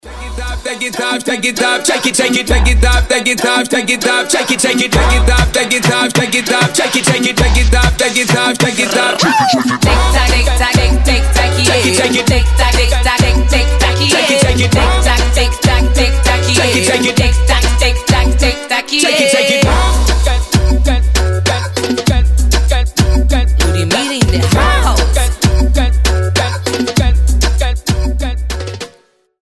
• Качество: 320, Stereo
ритмичные
заводные
dancehall
реггетон
Прикольный ритмичный рингтон